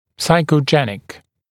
[ˌsaɪkə(u)ˈdʒenɪk][ˌсайко(у)ˈджэник]психогенный